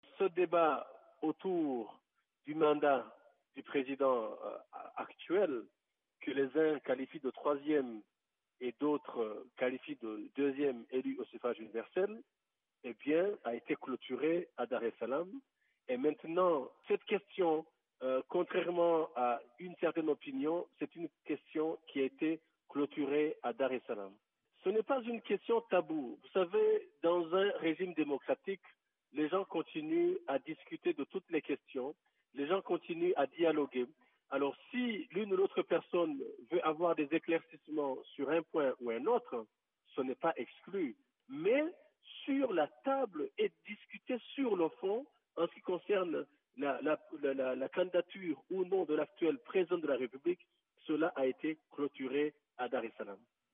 Joint par nos soins, W. Nyamitwe précise le sens qu’il donnait au mot "discuter".